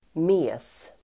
Uttal: [me:s]